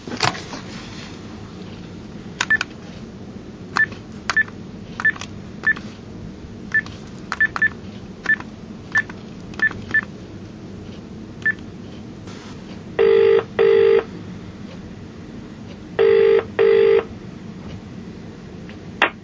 电话铃声
描述：这只是我的家庭电话铃声的记录，非常适合用于foley目的。
Tag: 振铃 电话 振铃 电话